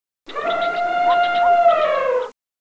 Tiếng Voi Kêu mp3